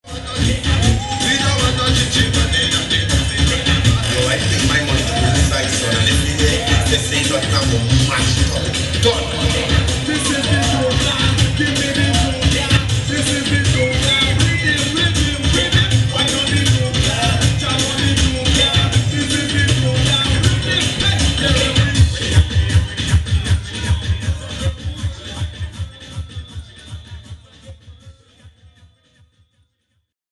watching the floats head up to central square towards cambridge city hall, i was treated to one of the bigger ironies of the day as
a song bu(r)ning chi-chi men (i.e., killing/denouncing gays) blasted from one of the trucks. listening more closely to the song, the anti-gay sentiments seem like more of a cliched afterthought--coming alongside such lines as "this is the tune ya" and "jah know the tune ya"--than a really purposeful attempt at songcraft, perhaps illustrating more a poverty of imagination than a full-blown bigotry. but that's no excuse. hearing such a song was, if unsurprising, kinda funny, considering that this is (the people's republic of) cambridge after all, where dozens of gay couples obtained marriage licenses a few months ago. but hey, we're multicultural. which is better than monocultural, even if it's wrong, too.